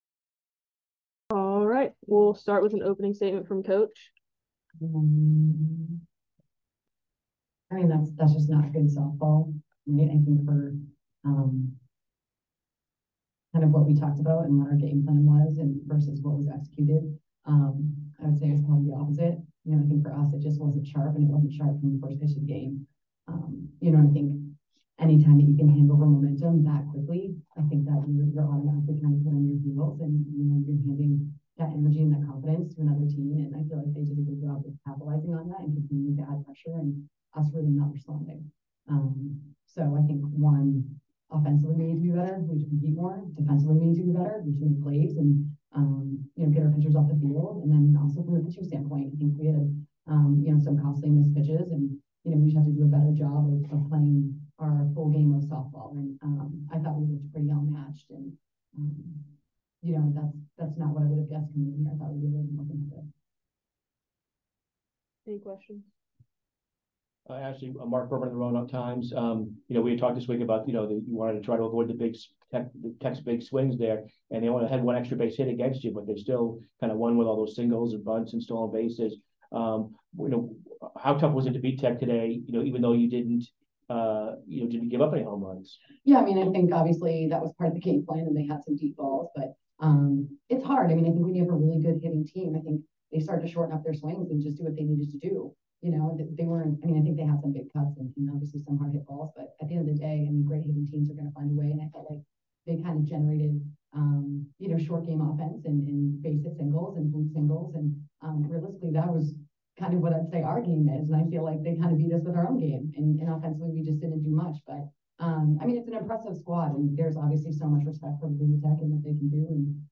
Virginia Tech Postgame Interview